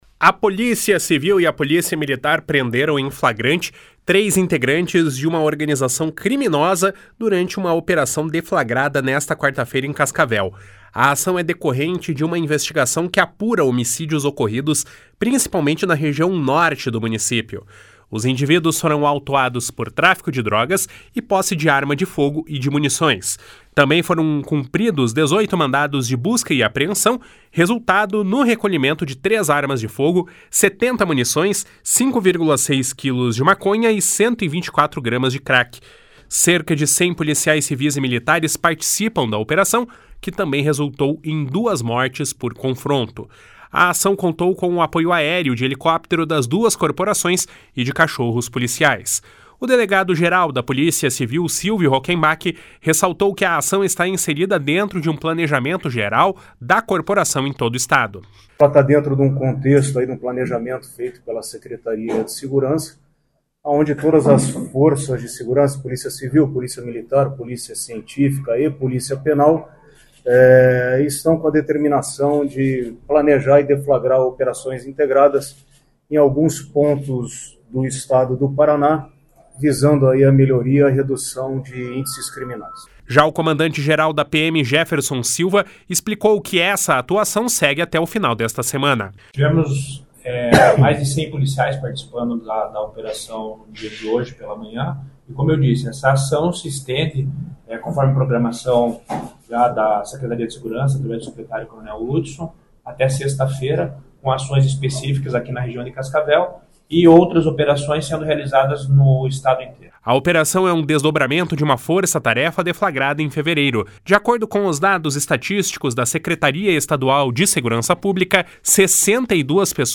A ação contou com o apoio aéreo de helicóptero das duas corporações e cachorros policiais. o delegado-geral da Polícia Civil, Silvio Rockembach, ressaltou que a ação está inserida dentro de um planejamento geral da corporação em todo o Estado.
O comandante-geral da PM, Jefferson Silva, explicou que essas atuação segue até o final desta semana.